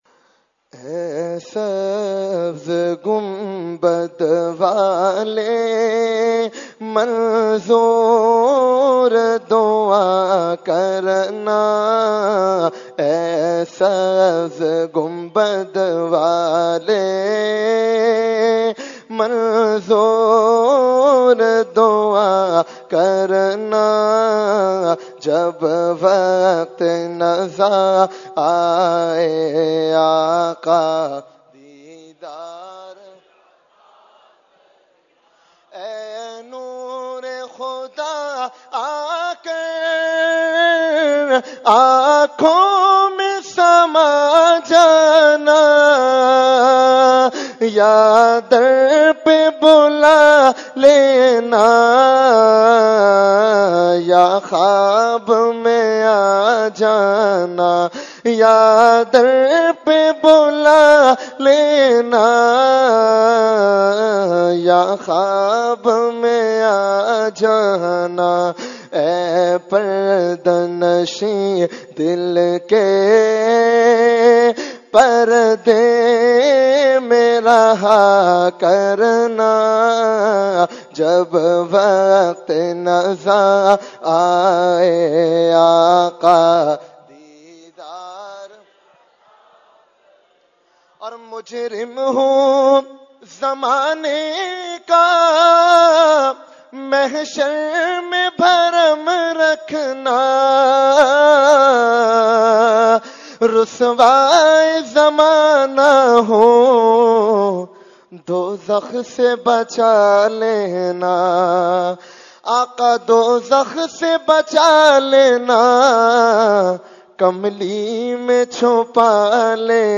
Category : Naat | Language : UrduEvent : Shab e Baraat 2018